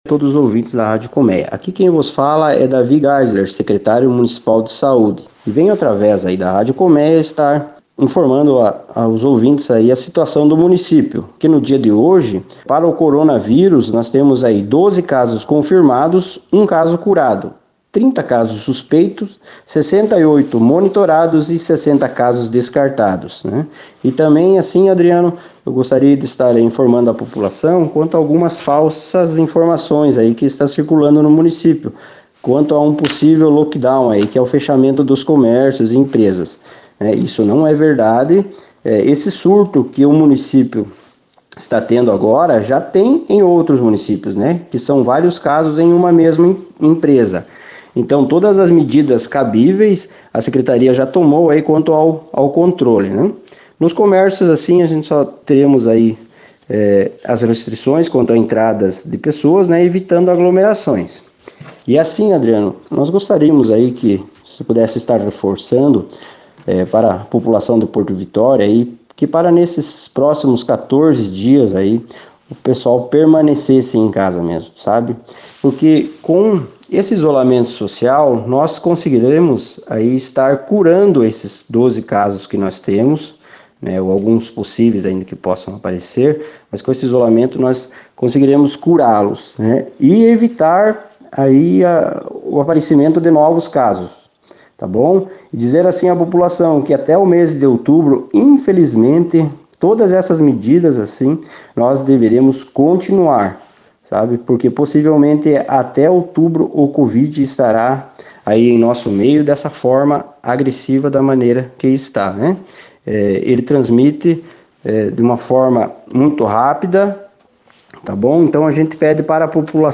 O Secretário Municipal de Saúde, Davi Gaesler, durante o Jornal Colmeia desta sexta-feira, 24, emitiu esclarecimentos sobre os casos e as “fake news”.